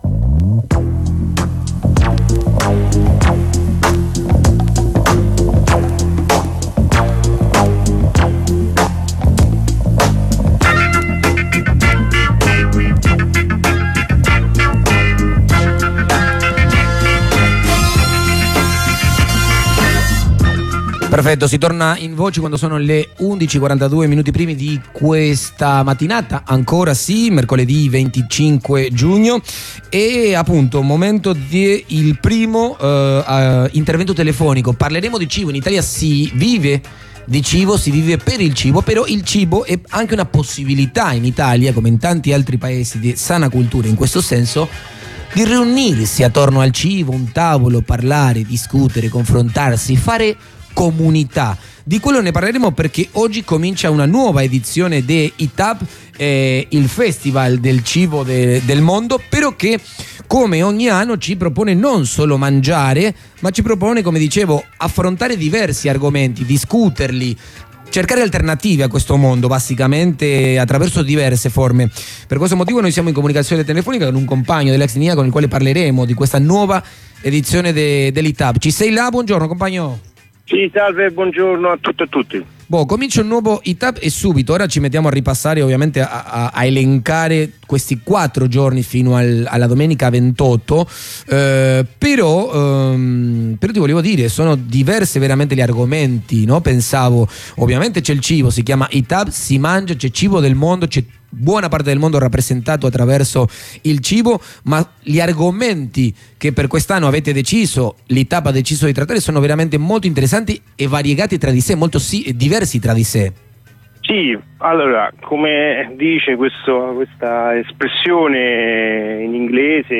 Comunicazione telefonica con un compagno del CSOA ExSnia